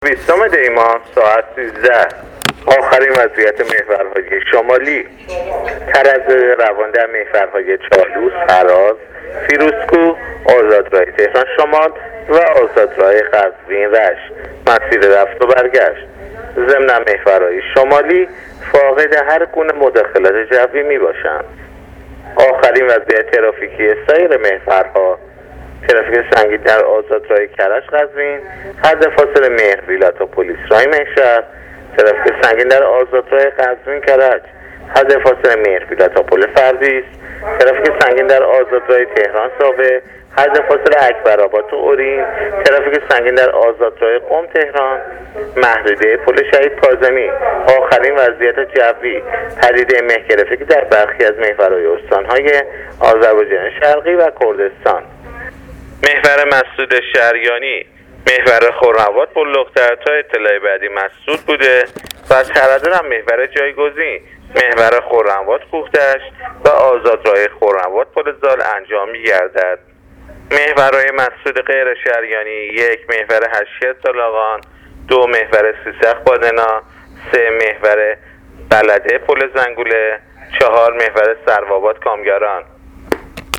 گزارش رادیو اینترنتی از آخرین وضعیت ترافیکی جاده‌ها تا ساعت ۱۳ بیستم دی؛